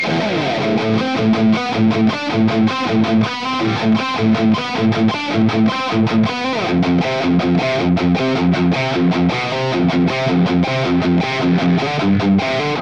It's fully loaded for any Hi-Gain application. 100 Watts of sofisticated Rock and Metal tones, It's all about gain!
Metal Riff
RAW AUDIO CLIPS ONLY, NO POST-PROCESSING EFFECTS